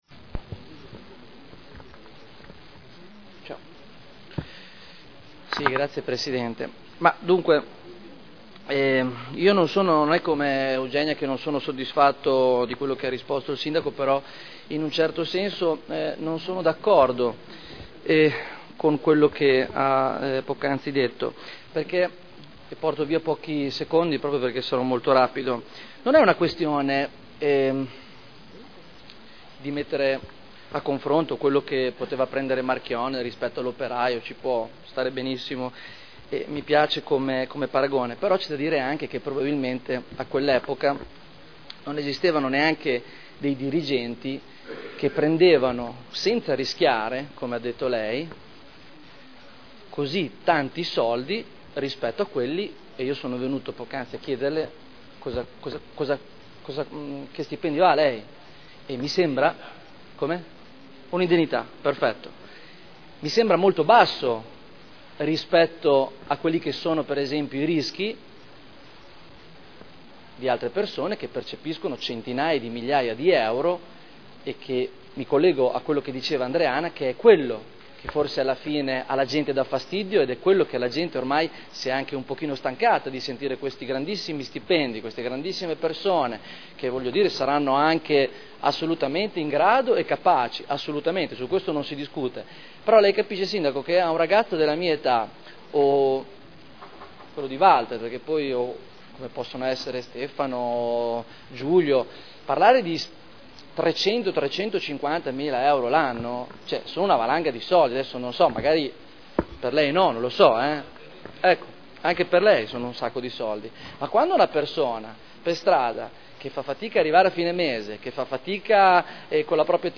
Stefano Barberini — Sito Audio Consiglio Comunale
Seduta del 11/04/2011. Dibatto sui cinque Ordini del Giorno presentati aventi per oggetto le "Nomine"